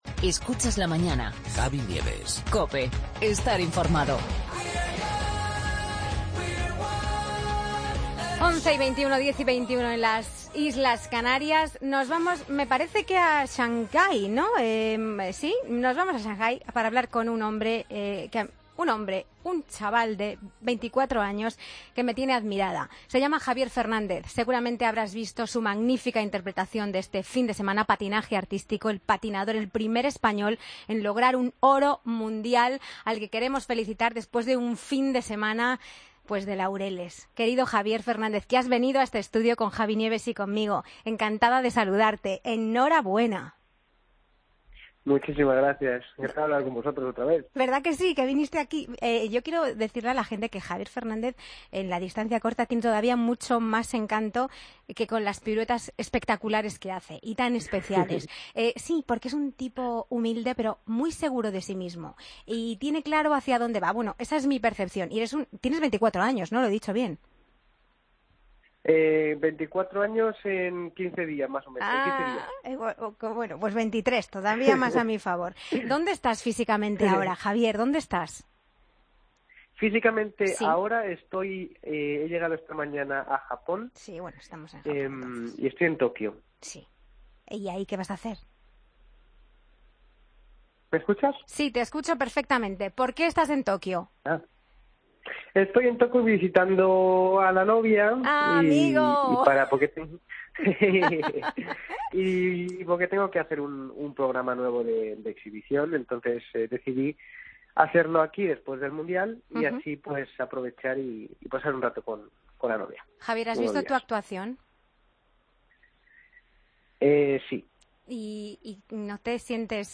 AUDIO: La Mañana de Javi Nieves habla con Javier Fernández, campeón del mundo de patinaje artístico.